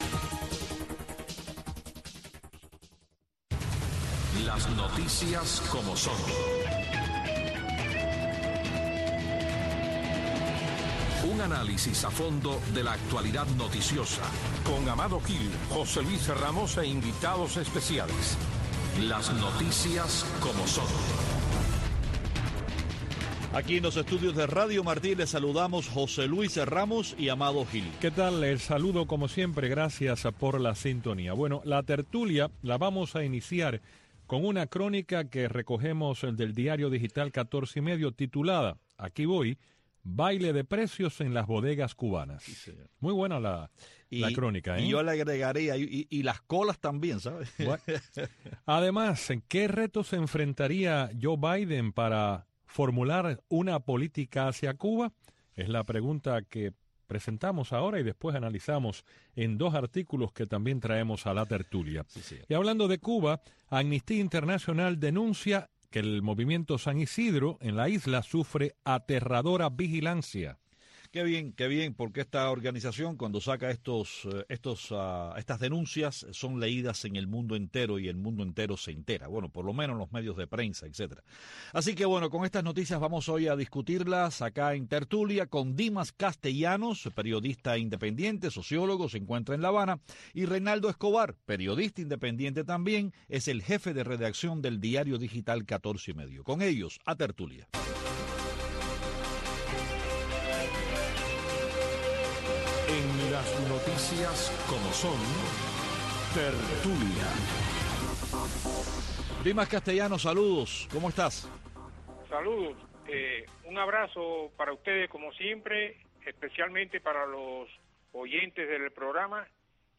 Una discusión dinámica y a fondo de las principales noticias del acontecer diario de Cuba y el mundo, con la conducción de los periodistas